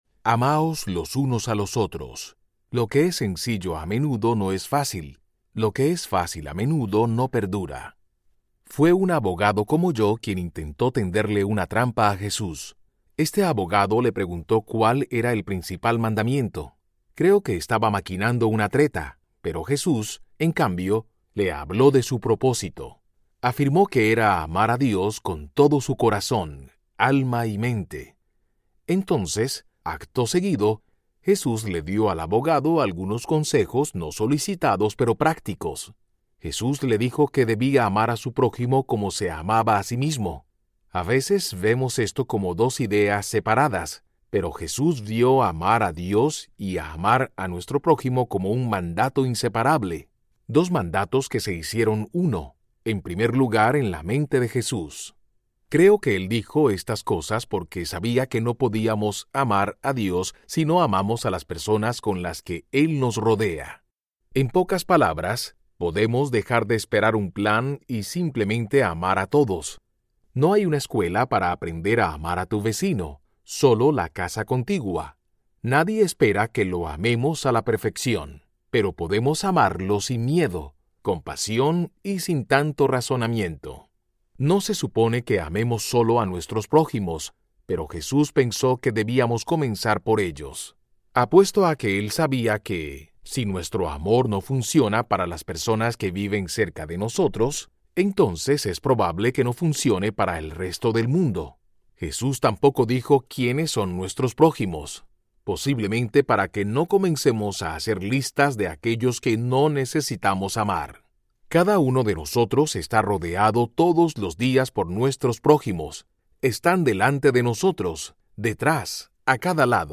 A todos, siempre Audiobook
Narrator
7.0 Hrs. – Unabridged